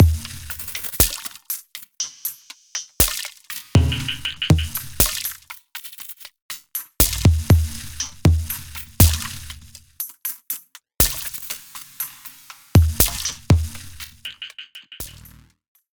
No other processing was done on these sounds other than the onboard effects included with the instrument.
This collection is designed from sampled Eurorack hardware:
Falcon-Modular-Noise.mp3